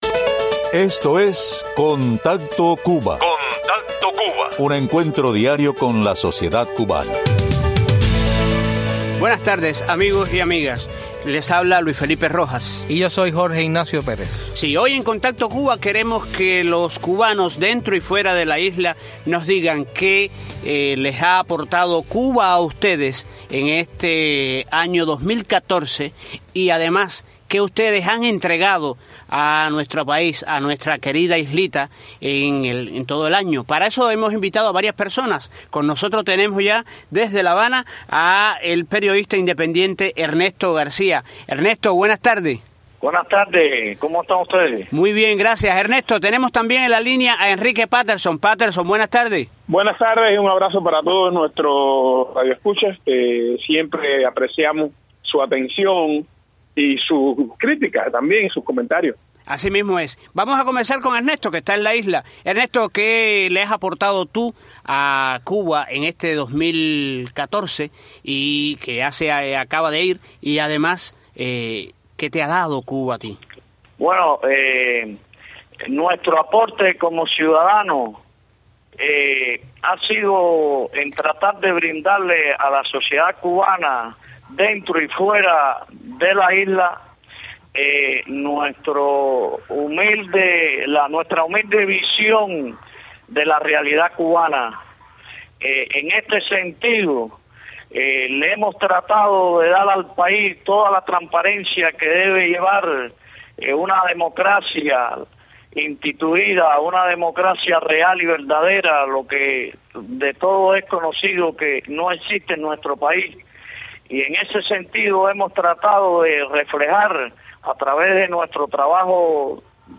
Entrevistas a integrantes de la sociedad civil indpendiente sobre sus metas para el 2015.